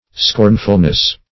Scorn"ful*ness, n.